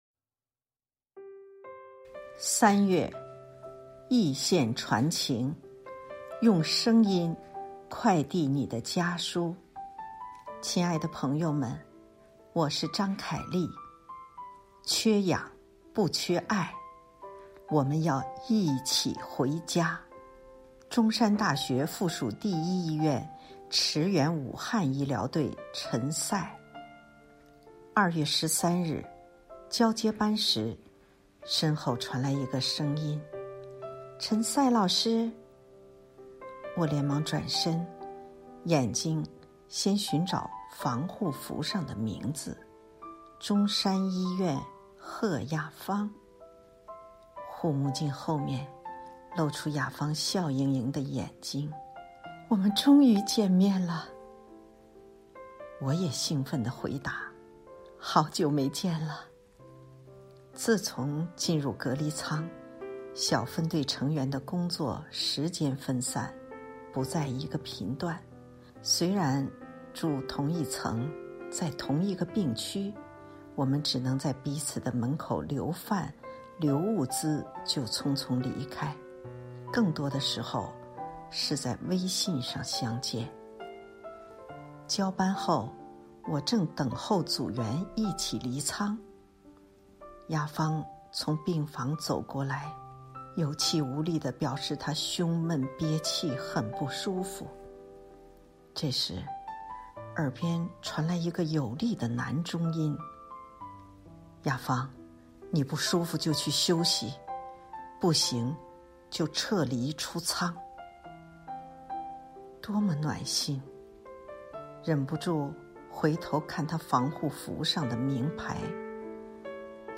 中國婦女雜誌社、學習強國聯合推出“聲傳家書致敬天使”主題活動，徵集戰疫家書，邀請康輝、鞠萍、凱麗、周濤、黃聖依、陳思思、李素麗、張銘恩、王筱磊、晨露等知名人士配樂朗讀家書，用聲音快遞你的深深牽掛，讓“她”聽見你的綿綿情意！
鞠萍姐姐代你讀家書！